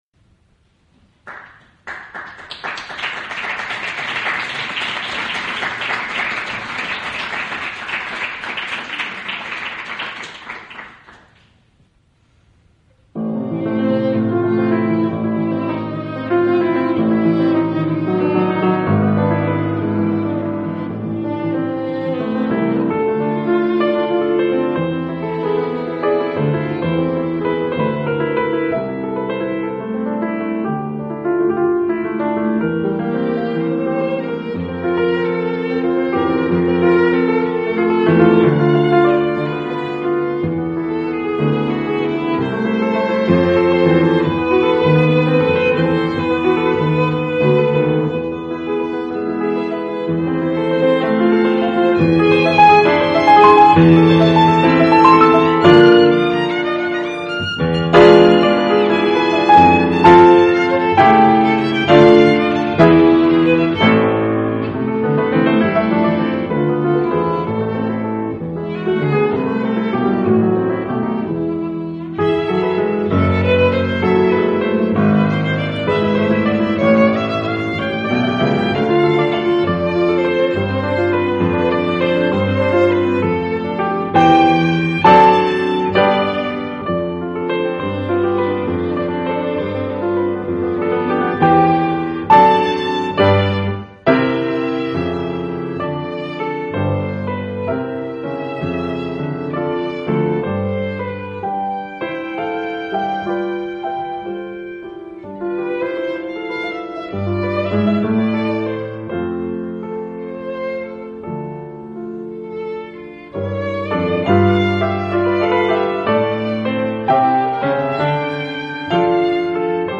Musiques en Tréfilerie 12 février 2004 :
piano
violon